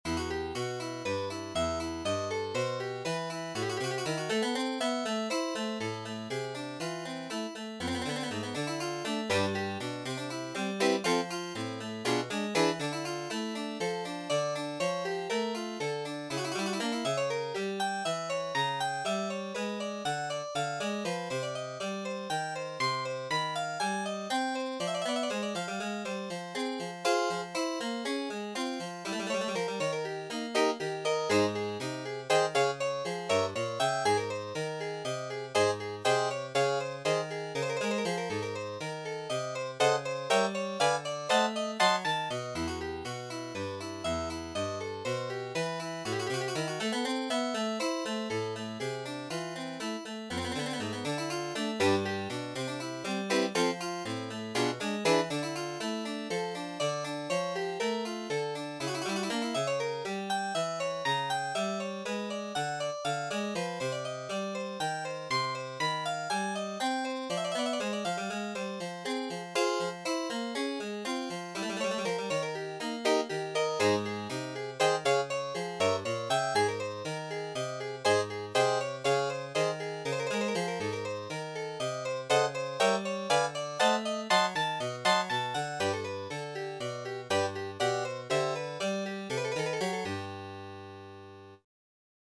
Another, recent piece, is a quick little exercise influenced by J. S. Bach,  titled "